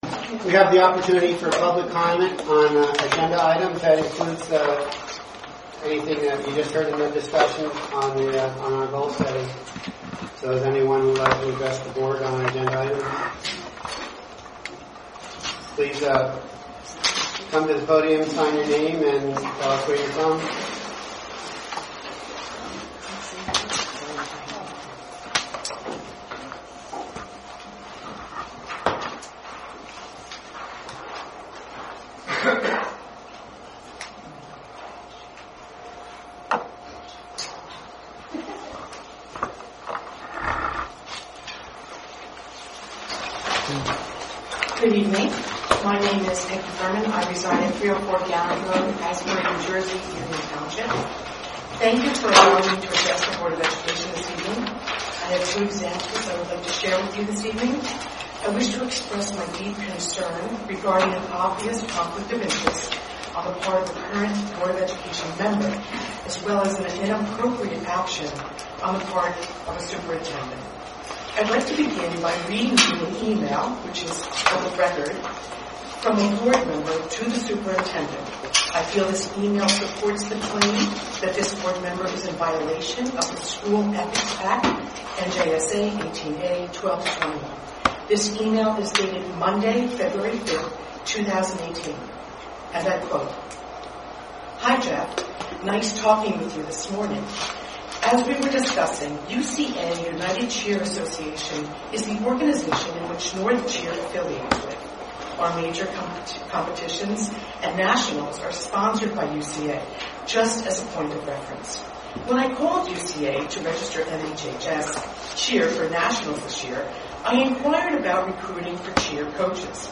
The full, official audio recording of the board of education meeting from which the above excerpts are taken is available on the NHV website.